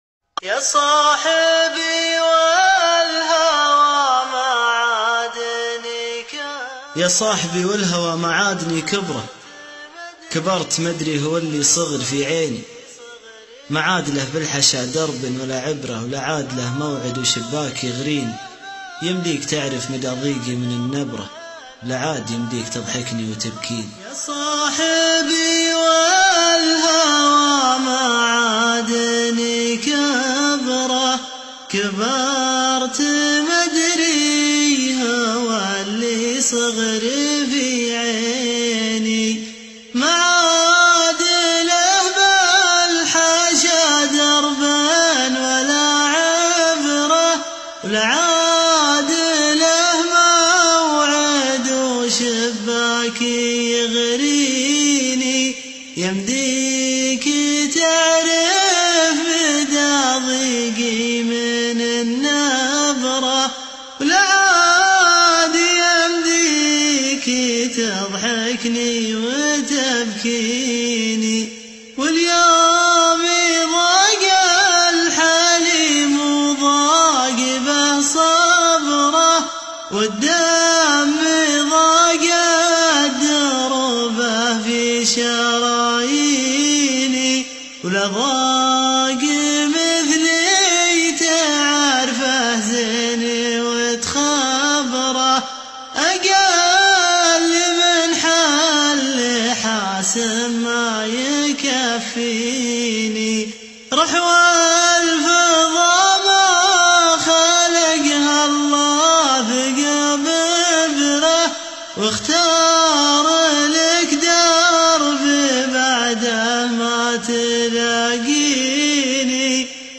شيلة حزينه .